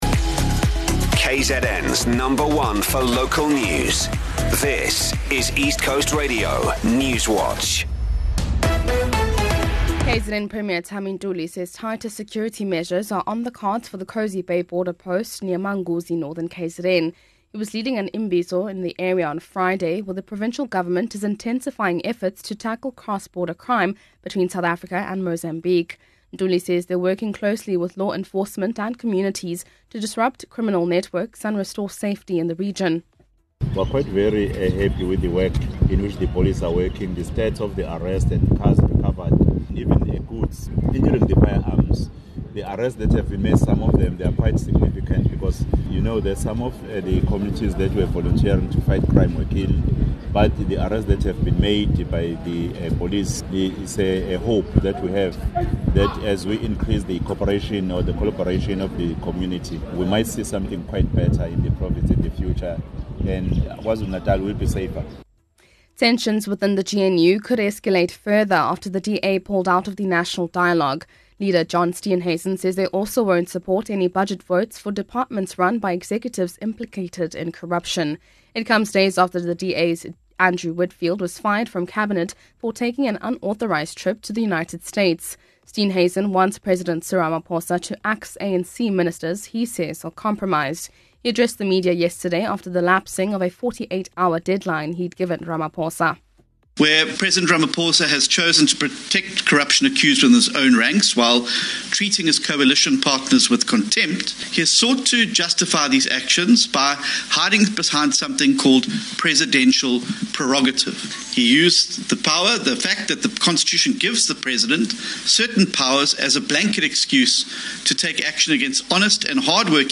Here’s your latest ECR Newswatch bulletin from the team at East Coast Radio.